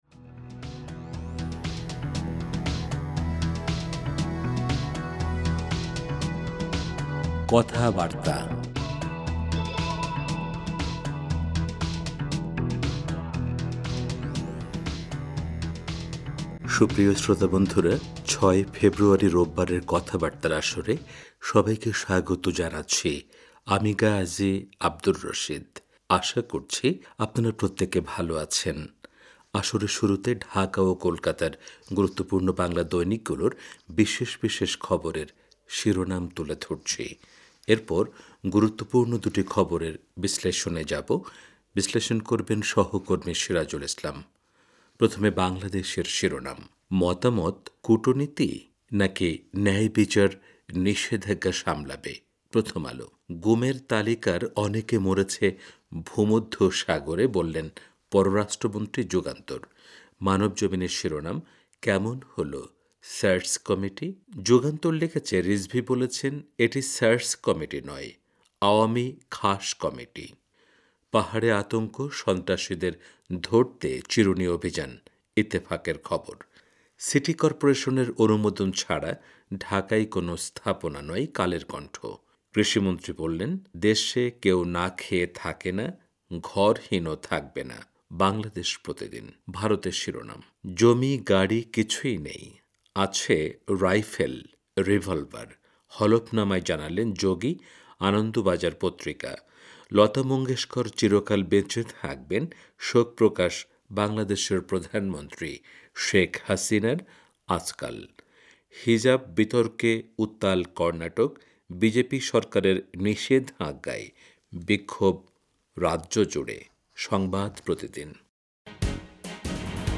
আসরের শুরুতে ঢাকা ও কোলকাতার গুরুত্বপূর্ণ বাংলা দৈনিকগুলোর বিশেষ বিশেষ খবরের শিরোনাম তুলে ধরছি। এরপর গুরুত্বপূর্ণ দুটি খবরের বিশ্লেষণে যাবো।